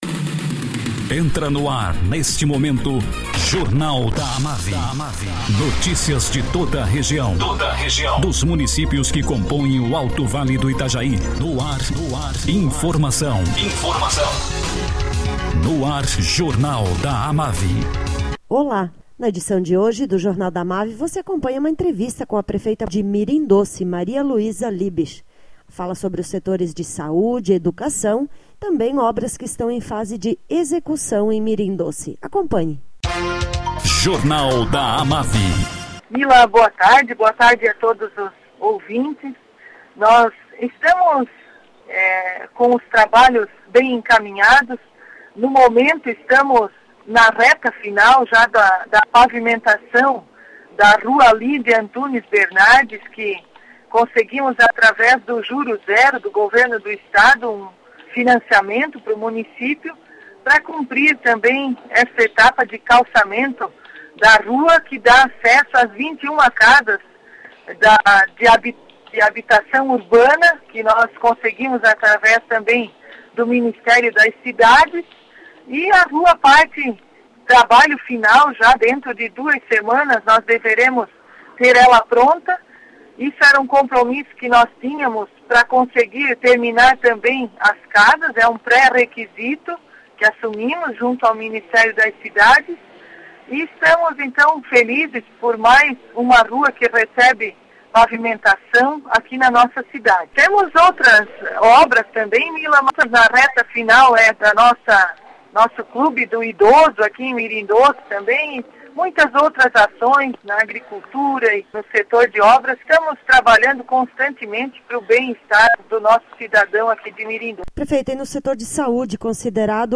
Prefeita de Mirim Doce, Maria Luiza Kestring Liebsch, fala sobre obras que estão sendo executadas no município e sobre setores de saúde e educação.